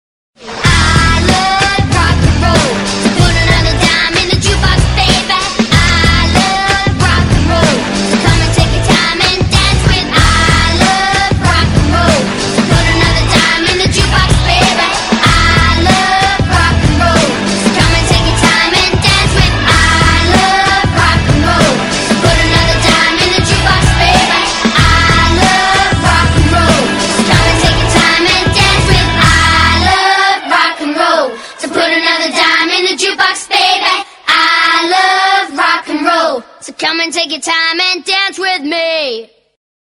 分类: DJ铃声
童 DJ舞曲